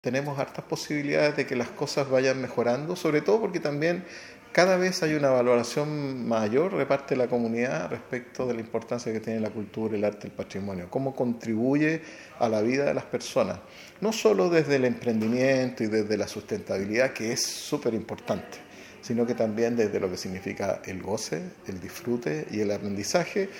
Conversatorio en el MUG: Avances y gestiones sobre patrimonio cultural
En la jornada expuso el director regional del Servicio Nacional de Patrimonio Cultural.